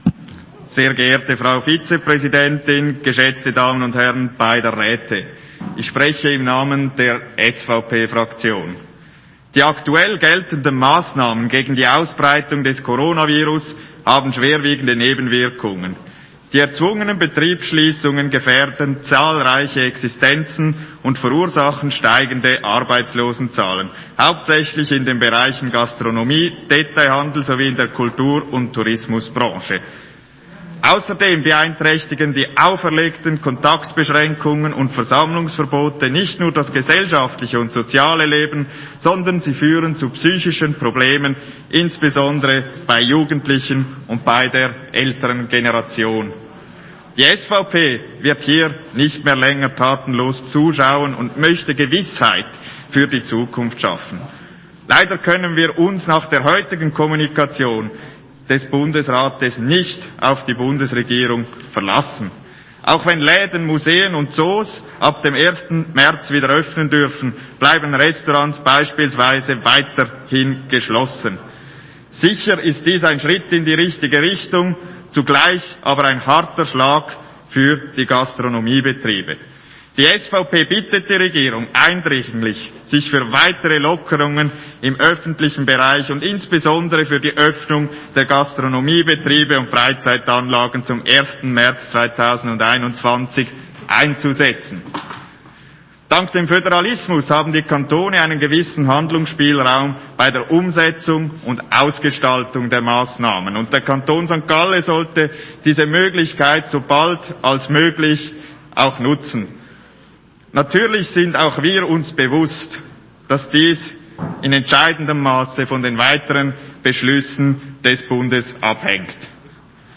17.2.2021Wortmeldung
Session des Kantonsrates vom 15. bis 17. Februar 2021